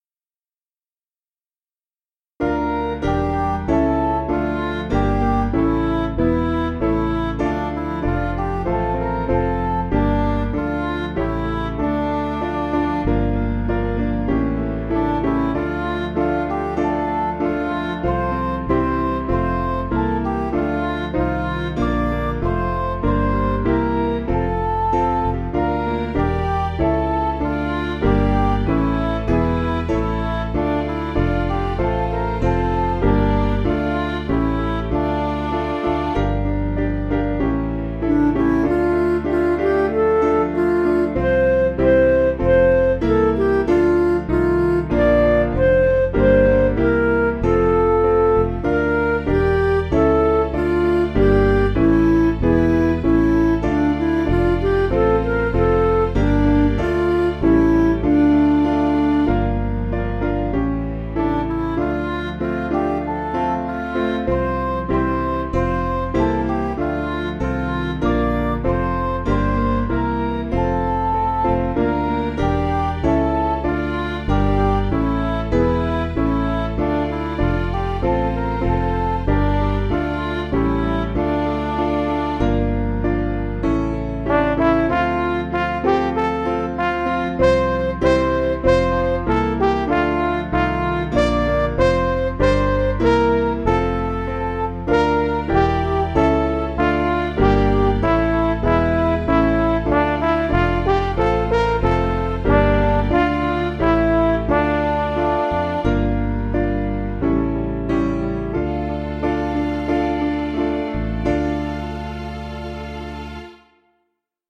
Piano & Instrumental
(CM)   4/Dm